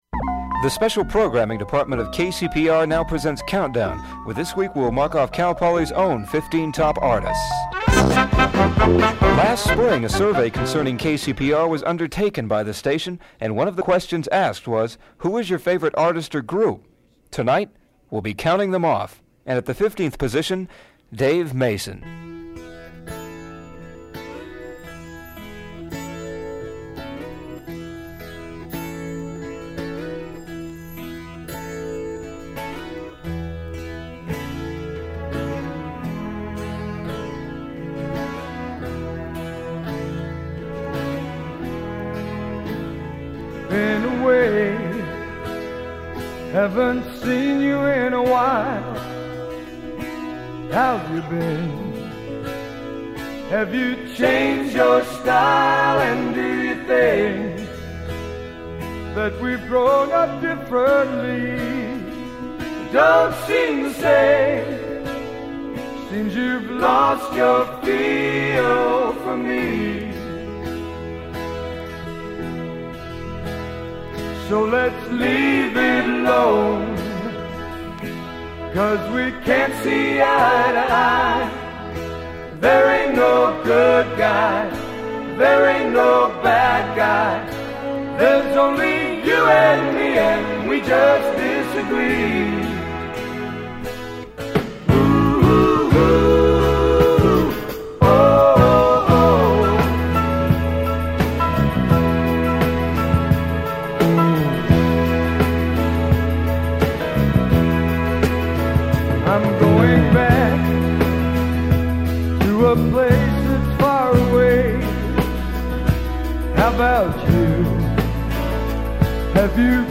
second half contains clipping
second half heavily damaged
tape damage less severe
Damage continues, ceased recording due to tape degradation
Open reel audiotape